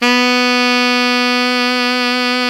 Index of /90_sSampleCDs/Roland L-CDX-03 Disk 1/SAX_Alto Tube/SAX_Alto ff Tube
SAX ALTOFF04.wav